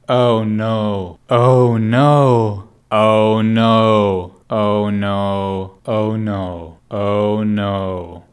Category 😂 Memes
disappoint disappointing disappointment english exclamation language no oh sound effect free sound royalty free Memes